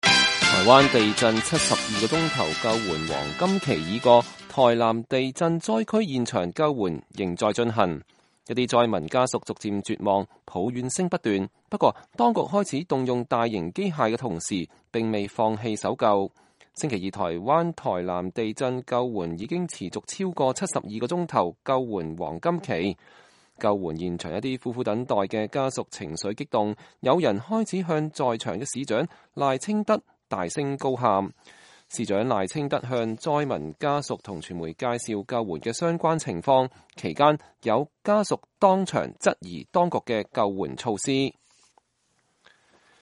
救援現場一些苦苦等待的家屬情緒激動，有人開始向在場的市長賴清德大聲高喊。